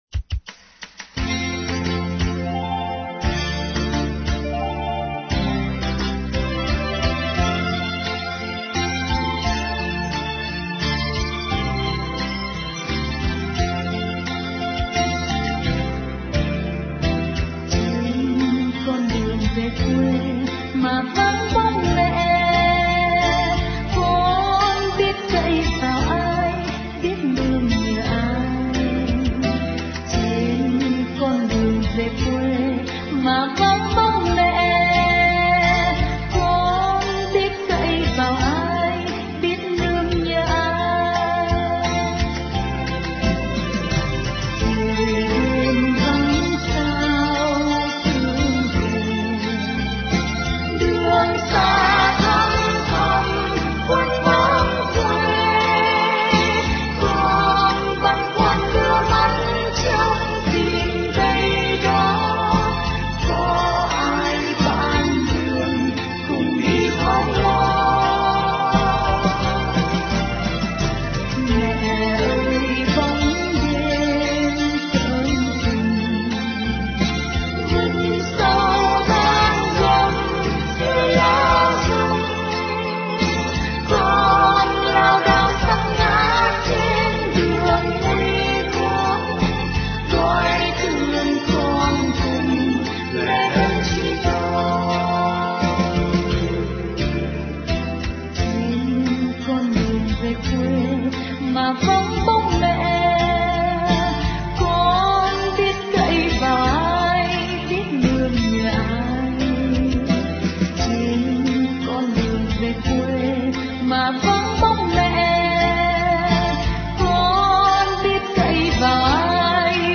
* Thể loại: Đức Mẹ